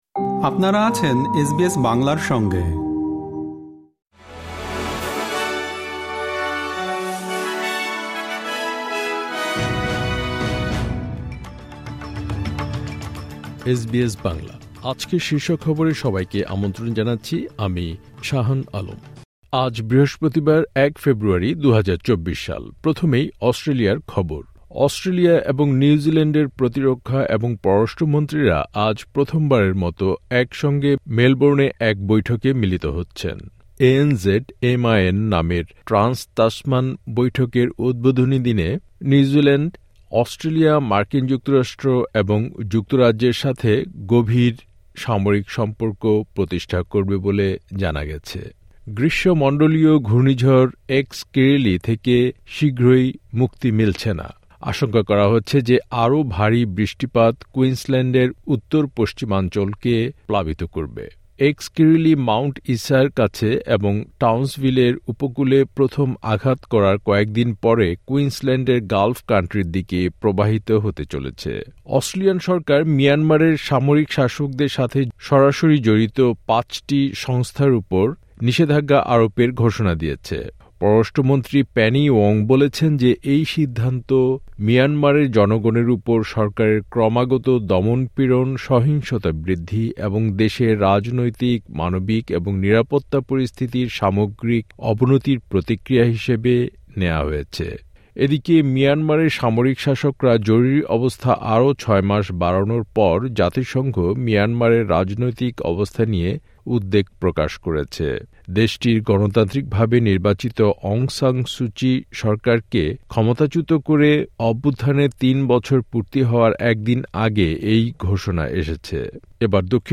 এসবিএস বাংলা শীর্ষ খবর: ১ ফেব্রুয়ারি , ২০২৪